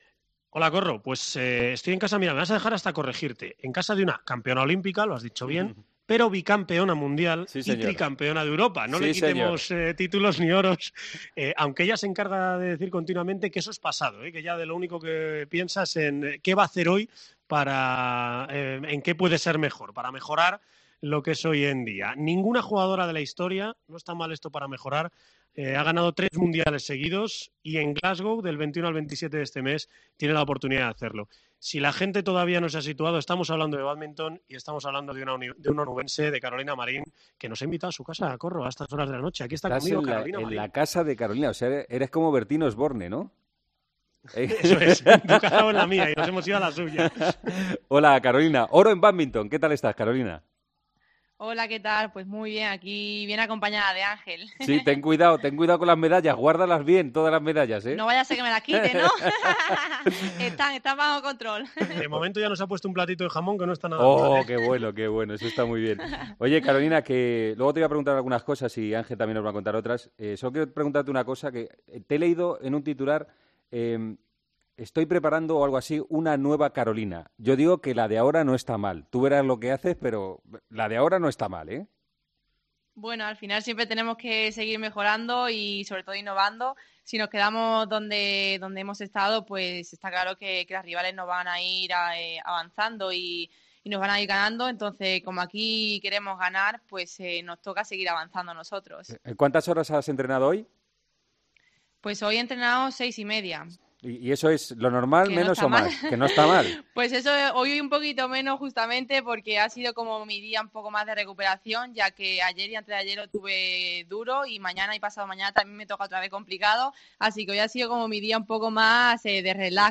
Entramos con El Partidazo de COPE en casa de Carolina Marín para hablar con la campeona olímpica